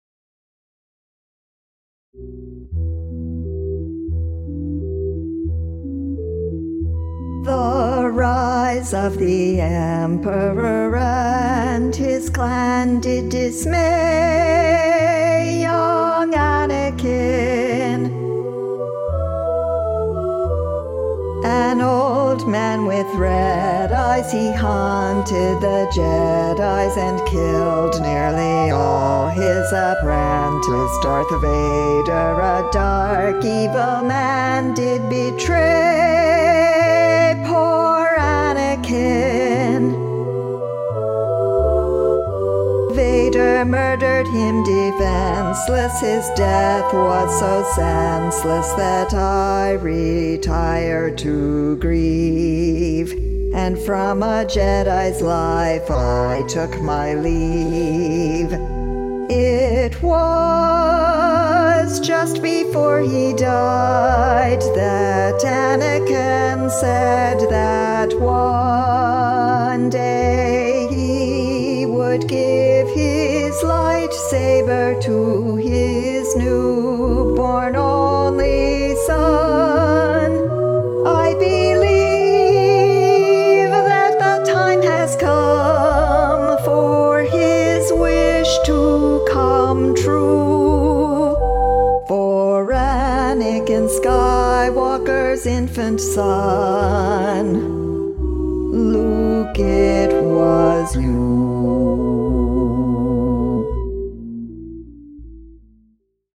Hear the passage with just Obi-Wan's part sung